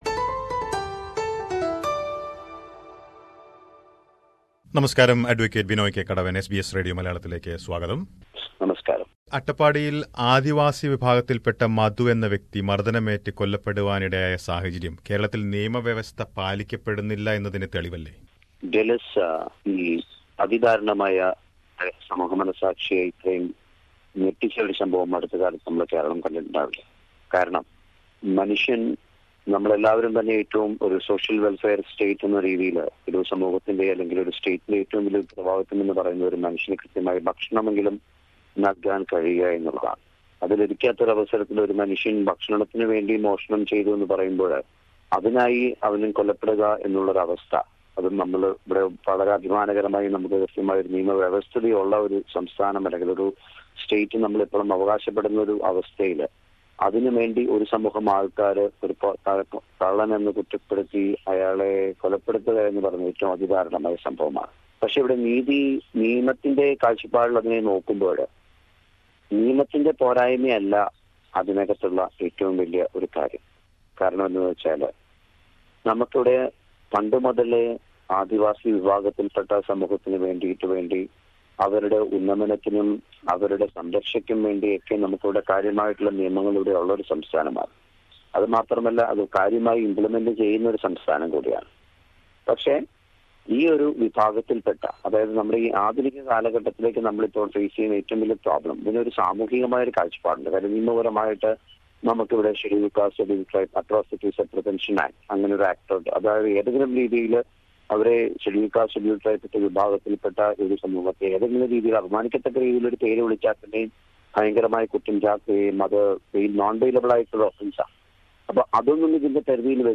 interview with an advocate